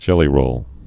(jĕlē-rōl)